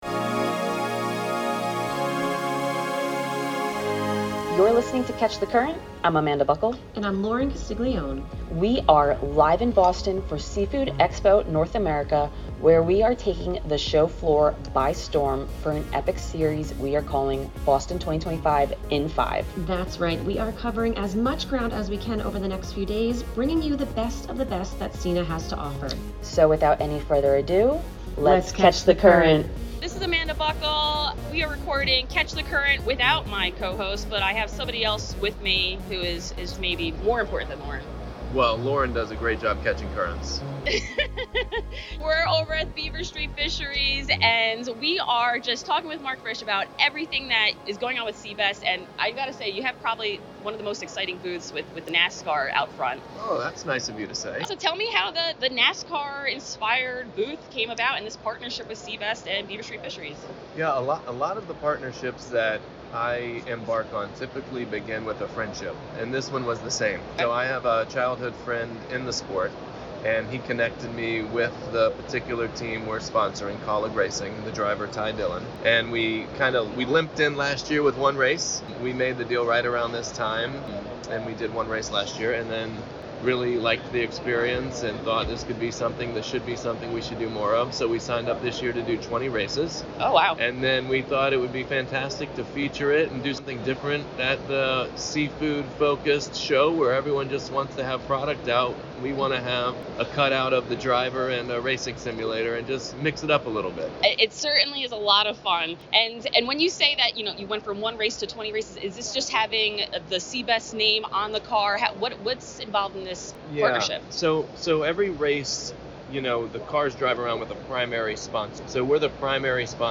live from the Seafood Expo North America show floor in Boston